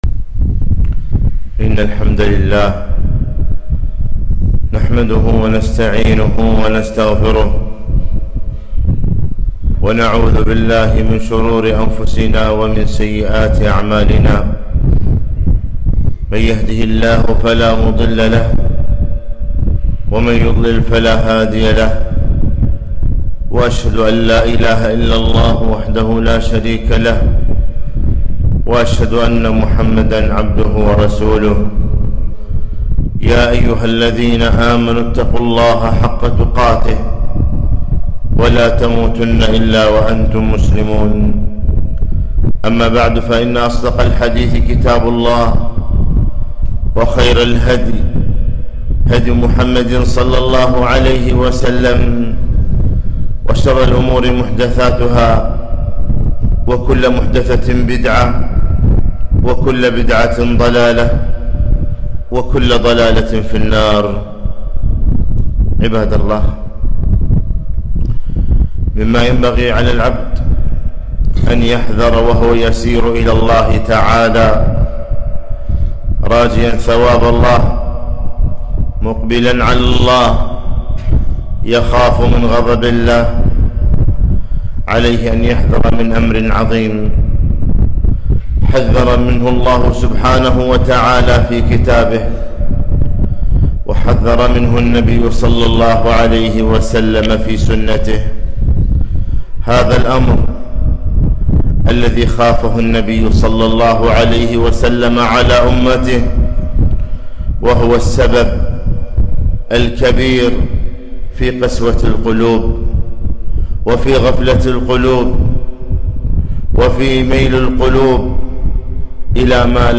خطبة - الحذر من الدنيا